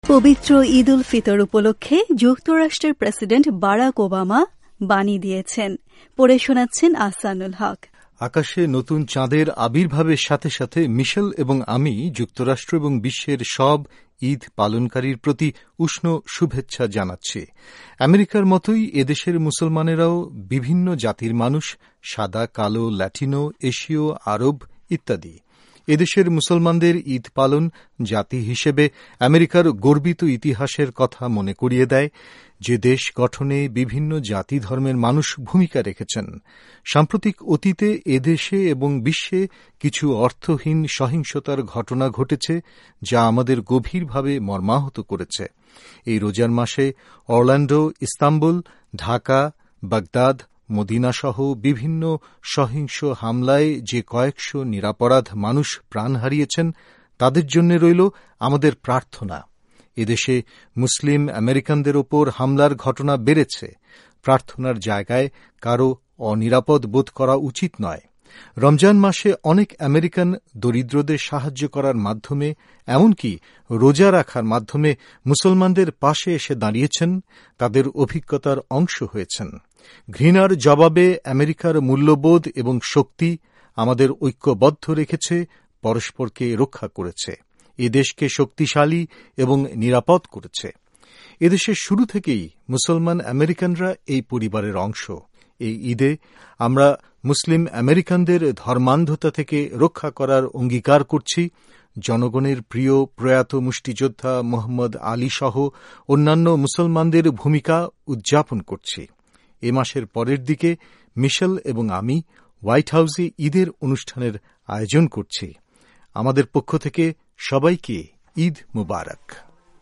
ঈদে প্রেসিডেন্ট বারাক ওবামার বাণী